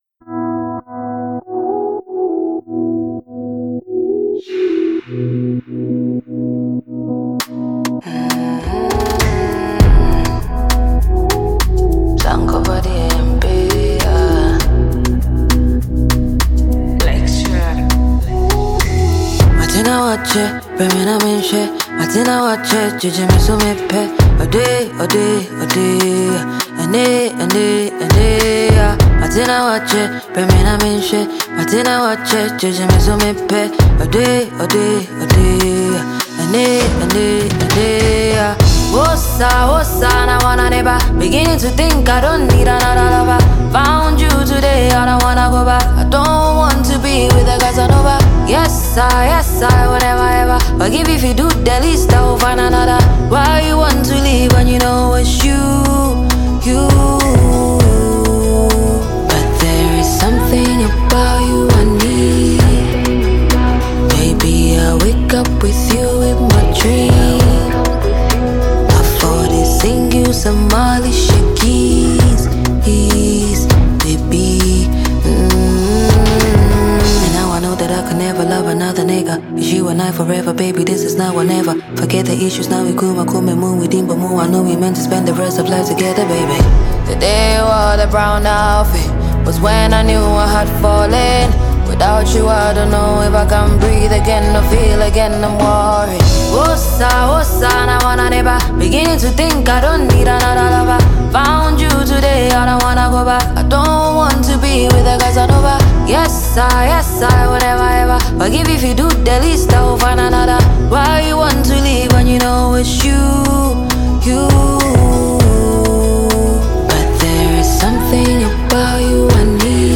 With her smooth vocals and heartfelt expression